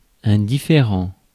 Ääntäminen
US : IPA : [ʌn.laɪk]